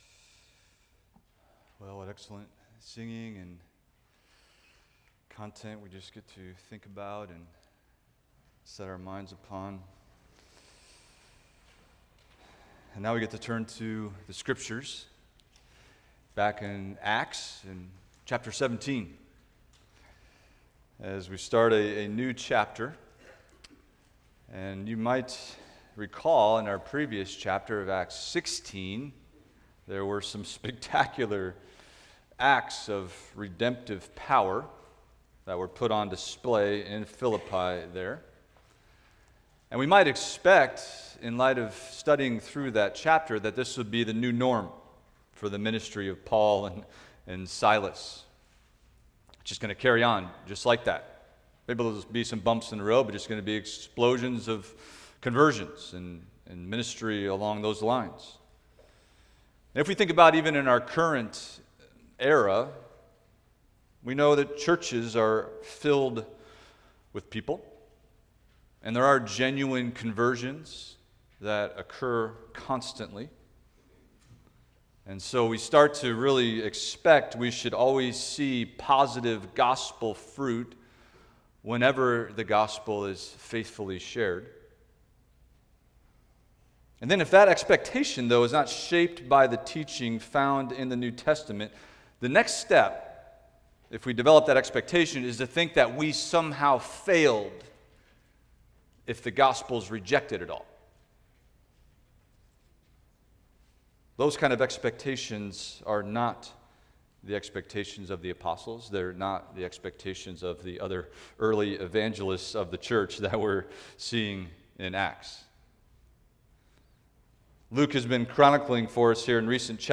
Expository Preaching from the Book of Acts – Acts 17:1-15 – The Disrupting Effect of the Gospel – Part 1